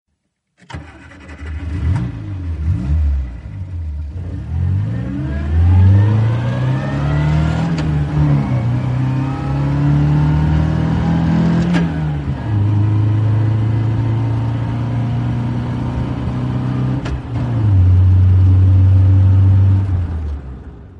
Sports Car Engine Sound Effect Free Download
Sports Car Engine